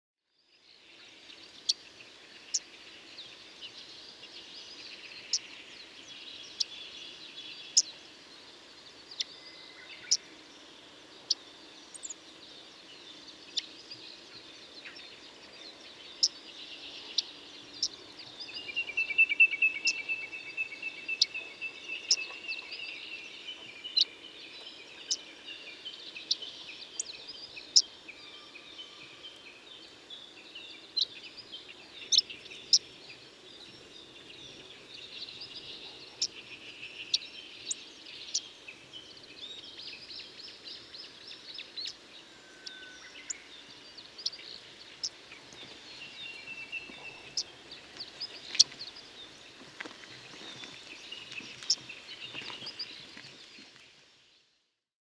chestnut-eared-bunting-call
Chestnut-eared-Bunting-Emberiza-fucata-2.mp3